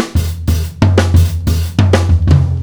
Chopped Fill 2.wav